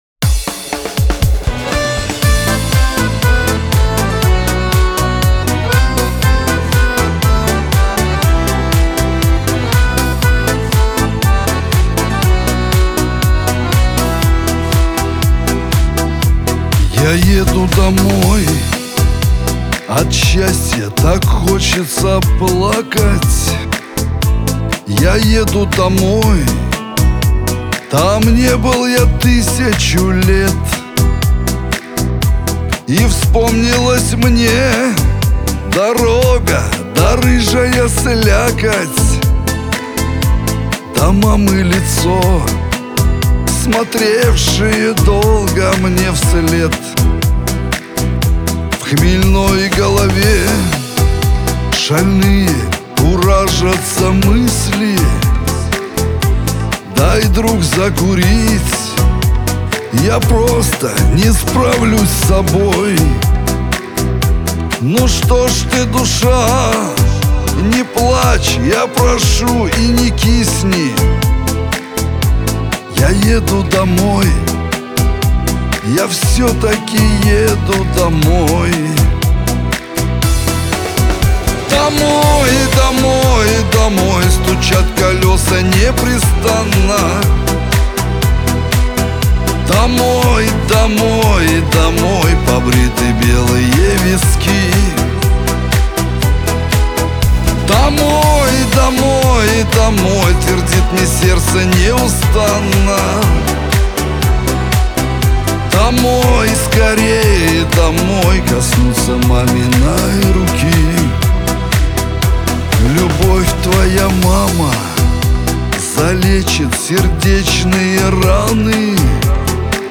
ансамбль , Шансон